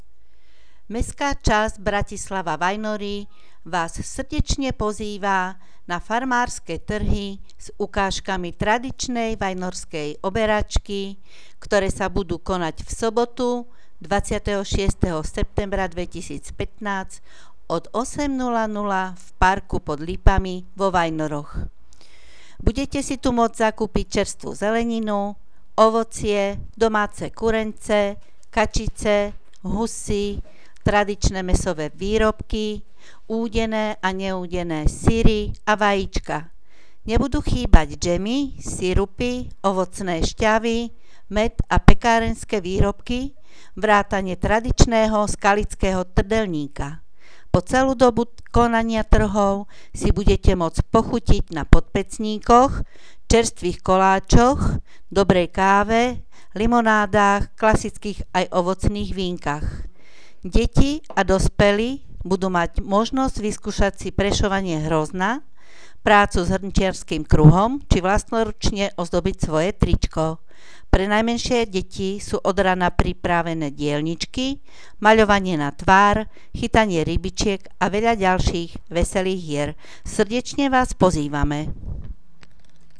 Farmárske trhy (hlásenie miestneho rozhlasu)